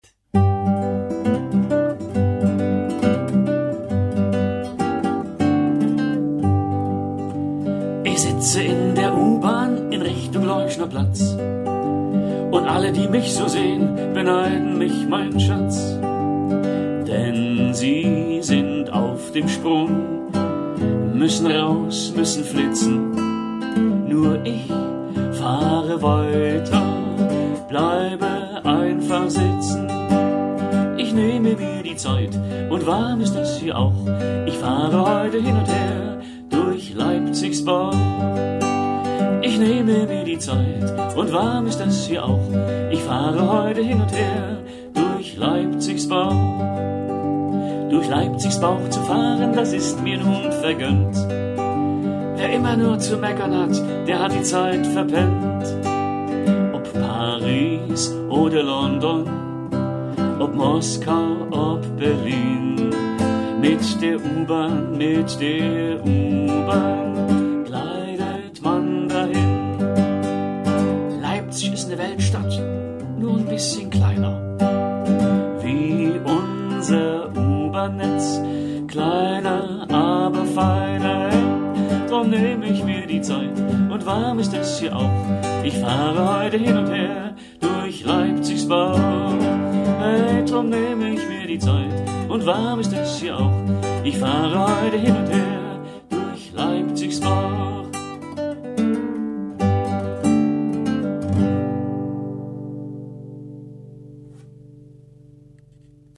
Aktuelle Presse Mögen Sie Live-Musik , Lieder zur Gitarre , mögen Sie Live-Programme von musikalisch-literarisch bis heiter-gesellig?